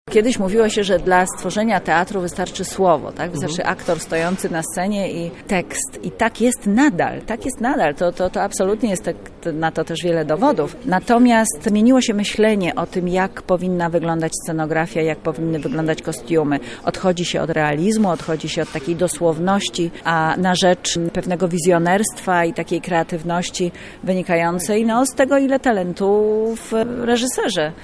Galę poprowadziła Grażyna Torbicka